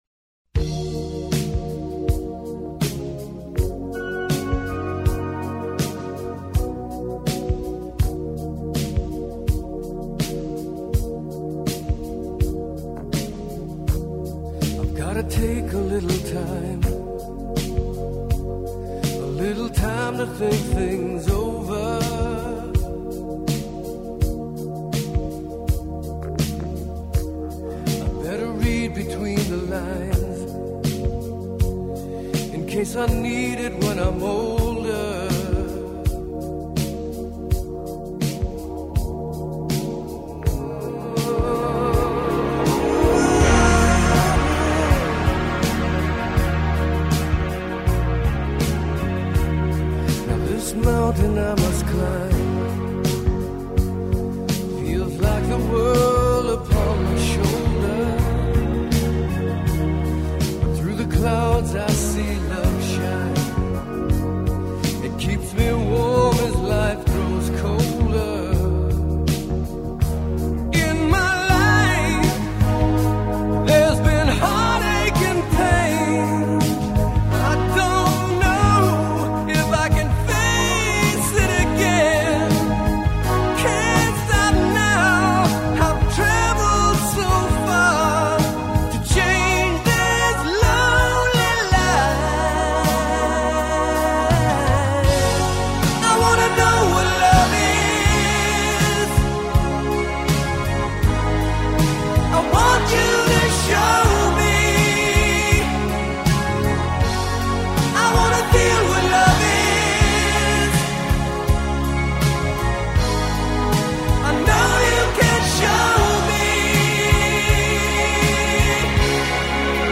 С пластинки